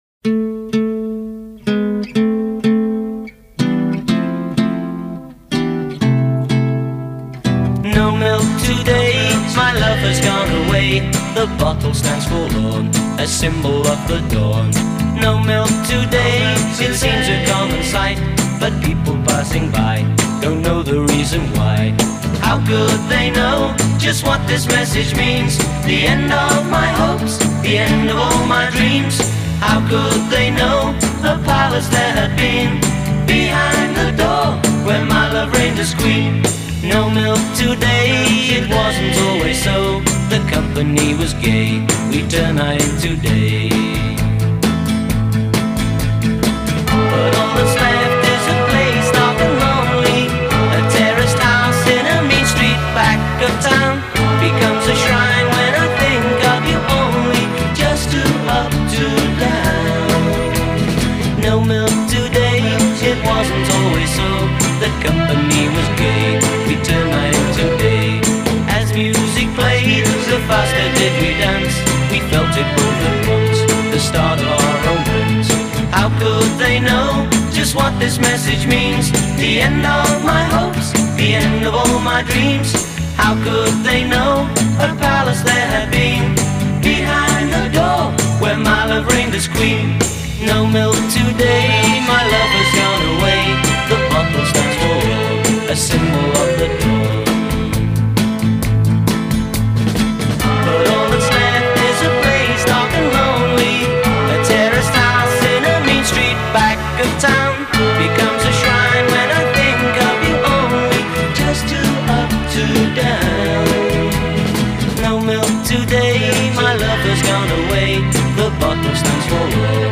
At least they’re both catchy little ditties.